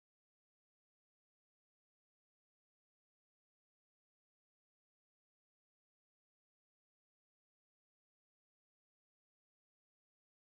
Bumblebee on Coneflower sound effects free download